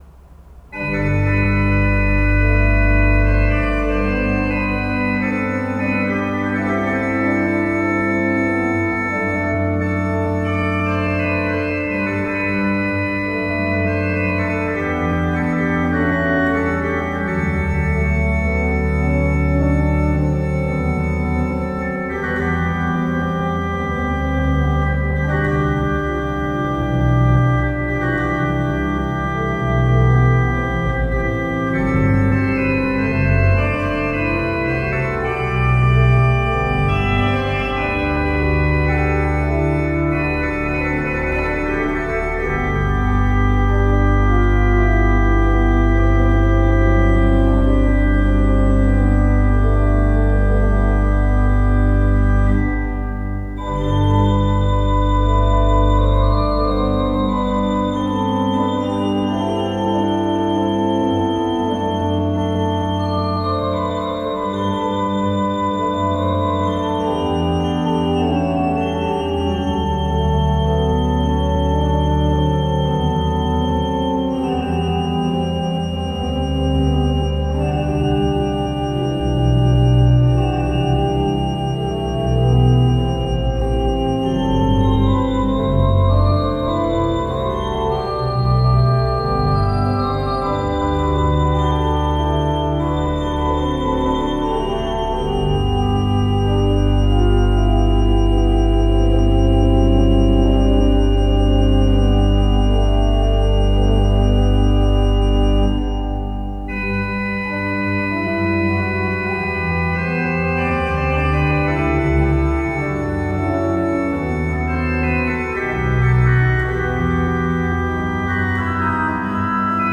Audio/Video - Organo Basilica Cattedrale di Fossano
Registrizioni amatoriali realizzate con Zoom H5N con capsula Zoom Msh-6 e/o microfoni esterni stereo AKG SE300B
Brani periodo Barocco